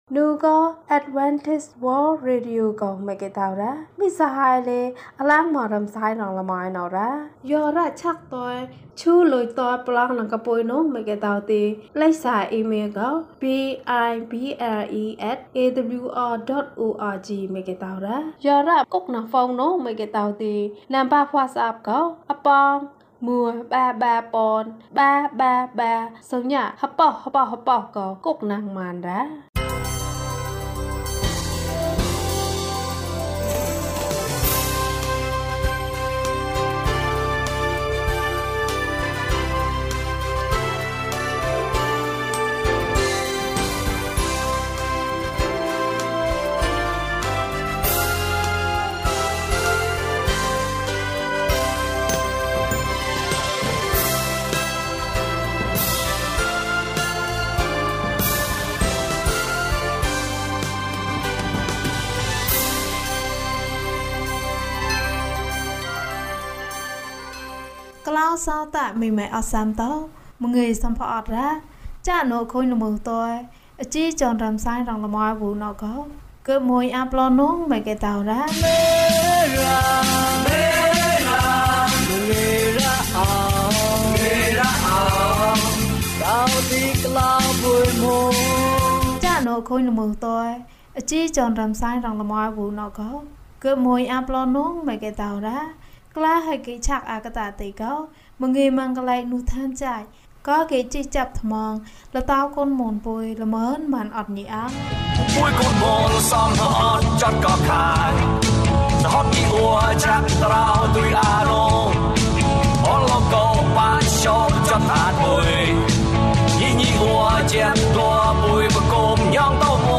ယုဒဘဝ။ ကျန်းမာခြင်းအကြောင်းအရာ။ ဓမ္မသီချင်း။ တရားဒေသနာ။